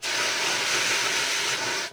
extinguisher.wav